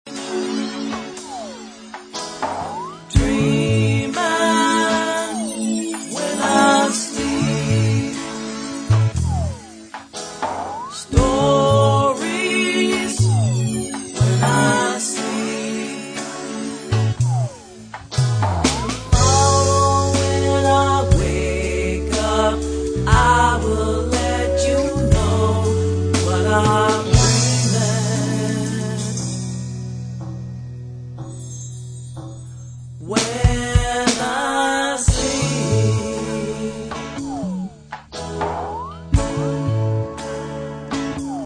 a collection of slower tempo songs that encourage quiet time